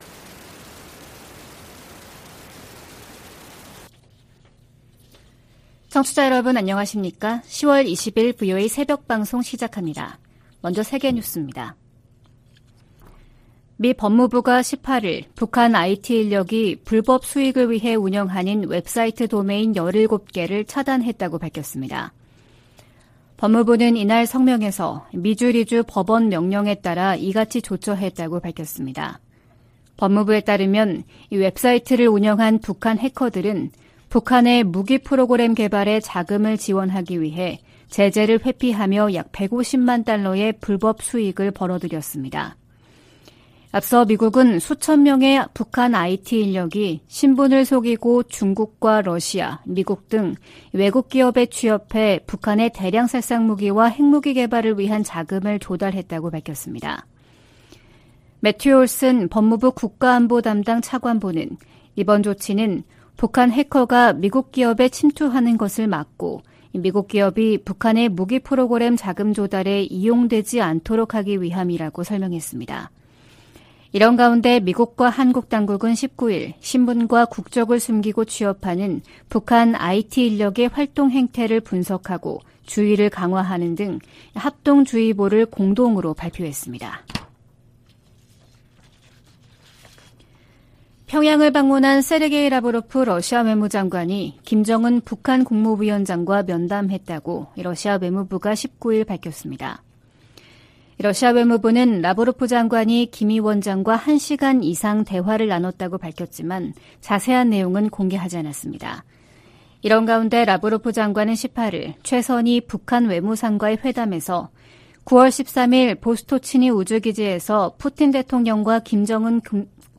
VOA 한국어 '출발 뉴스 쇼', 2023년 10월 20일 방송입니다. 북한을 방문한 세르게이 라브로프 러시아 외무장관은 양국 관계가 질적으로 새롭고 전략적인 수준에 이르렀다고 말했습니다. 미 상원의원들은 북-러 군사 협력이 러시아의 우크라이나 침략 전쟁을 장기화하고, 북한의 탄도미사일 프로그램을 강화할 수 있다고 우려했습니다. 북한이 암호화폐 해킹을 통해 미사일 프로그램 진전 자금을 조달하고 있다고 백악관 고위 관리가 밝혔습니다.